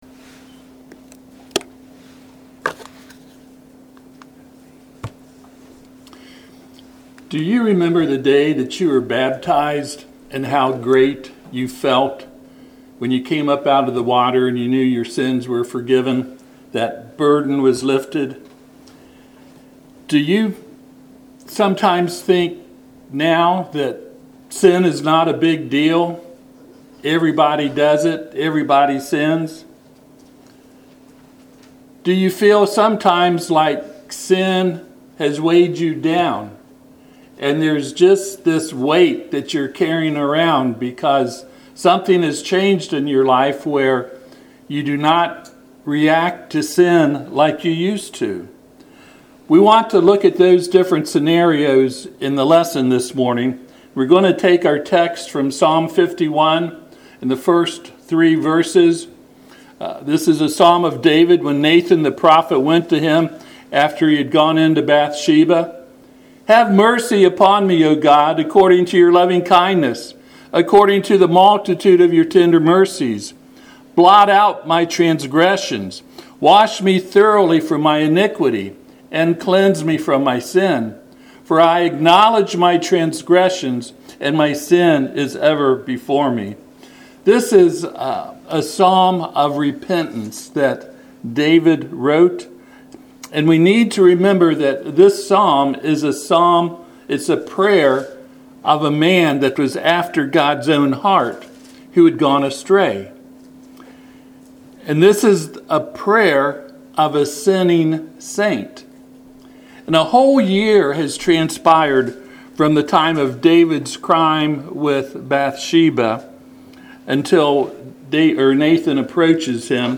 Psalm 51 Passage: Psalm 51:1-4 Service Type: Sunday AM https